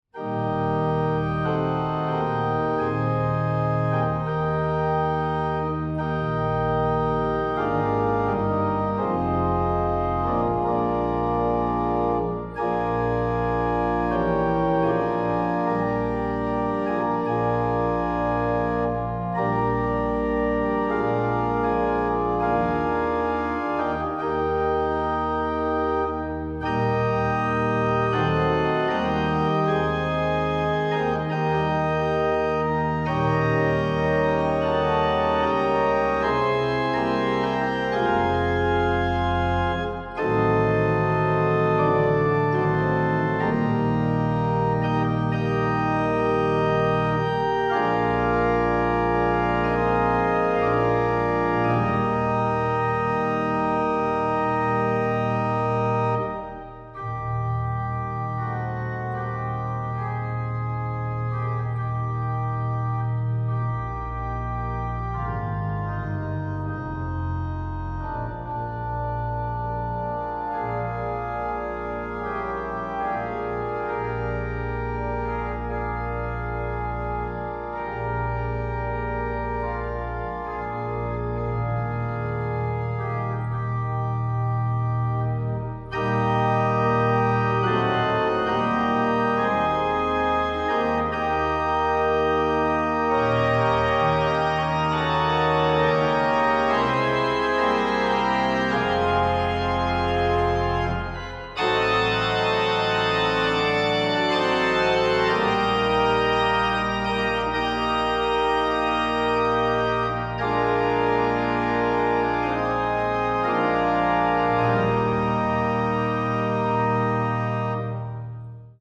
Spelman Hymn words and music by Eddye Money Shivery, C’34 Sheet Music (PDF) Spelman Hymn (Organ Only)
spelman-hymn-organ-only.mp3